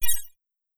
SciFiNotification1.wav